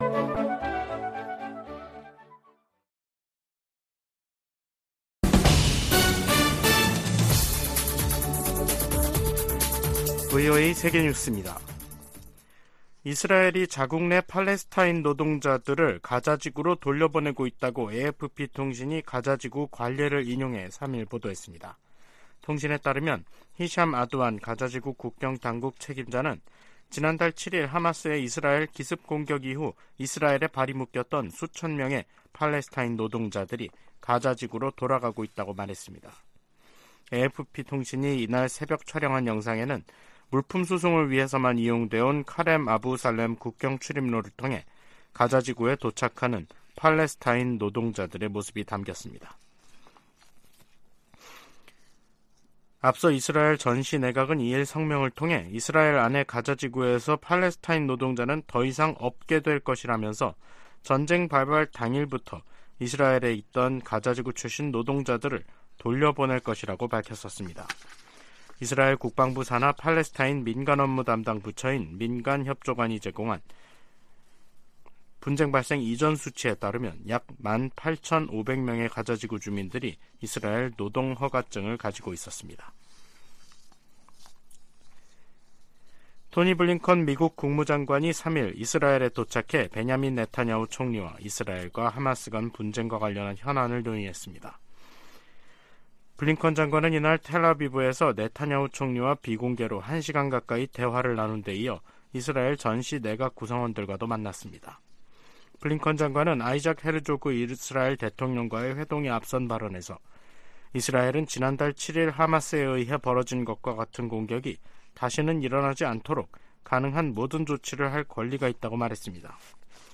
VOA 한국어 간판 뉴스 프로그램 '뉴스 투데이', 2023년 11월 3일 3부 방송입니다. 다음 주 한국을 방문하는 토니 블링컨 미 국무장관이 철통 같은 방위 공약을 강조할 것이라고 국무부가 밝혔습니다. 로이드 오스틴 국방장관도 잇따라 한국을 방문합니다.